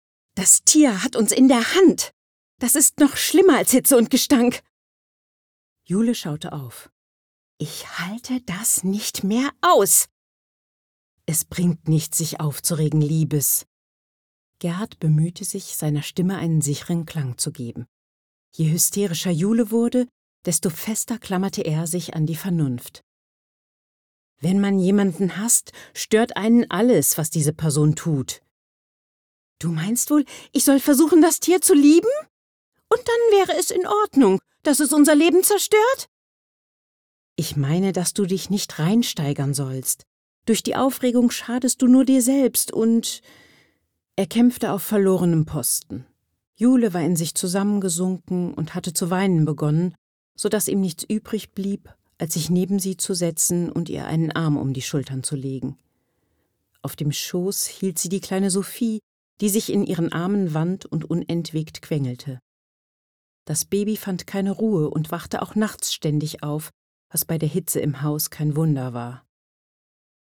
Roman (Demo)
warm, markant, tief, beruhigend, erzählerisch, vernünftig, psychologisch, seriös